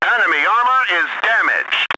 hit6.ogg